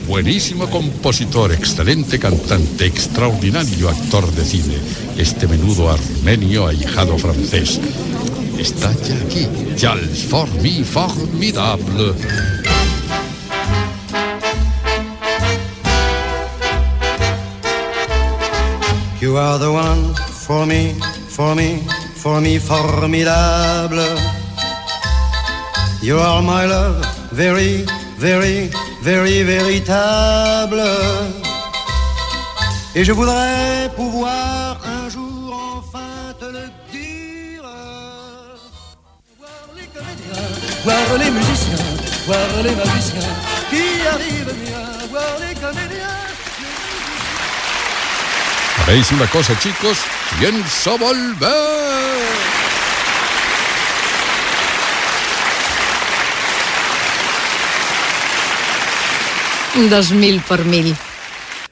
Presentació d'un tema musical, comiat i indicatiu del programa.
Musical
FM